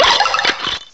cry_not_vanilluxe.aif